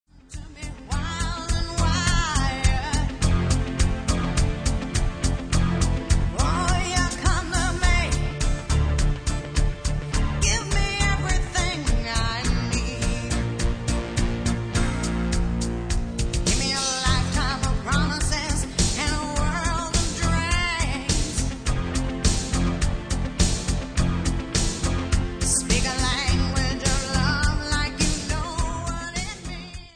R&B KARAOKE MUSIC CDs
w/vocal